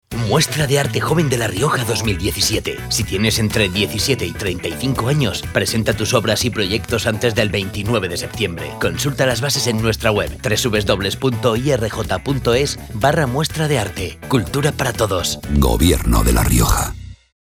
Elementos de campaña Cuñas radiofónicas Cuña genérica. Centro de Apoyo a la Familia.